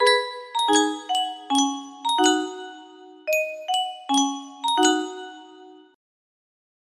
time to conquer india!!1! music box melody
Full range 60